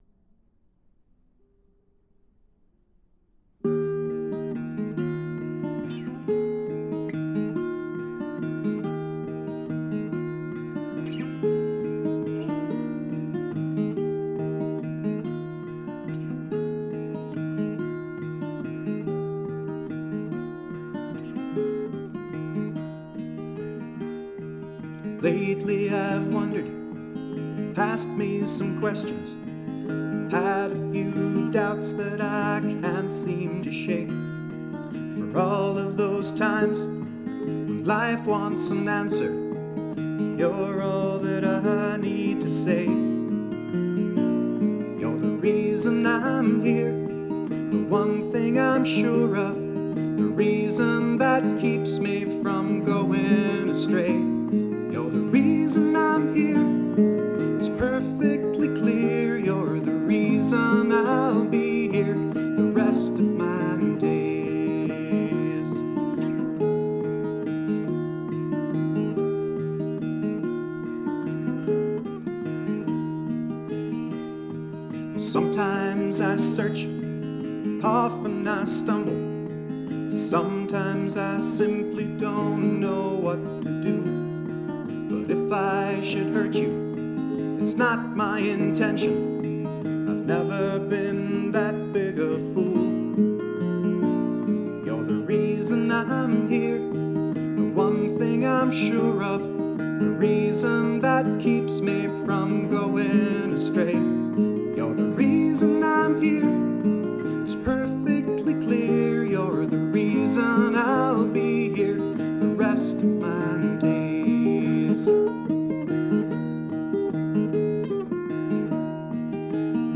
Musically, it’s a waltz.
guitar & vocals